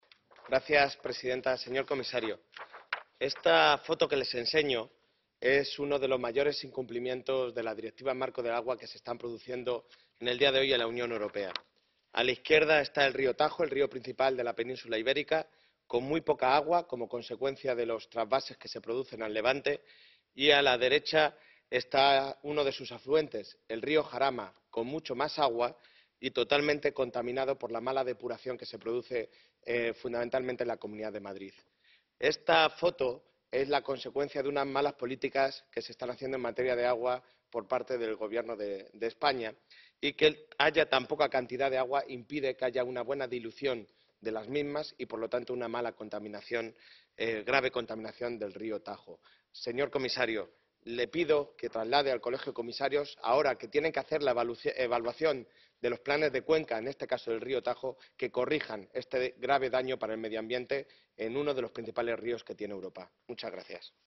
El eurodiputado socialista, en una intervención en el Parlamento Europeo y en una carta a Juncker, denuncia la situación de "emergencia y de extrema gravedad" del río